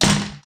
Slam.ogg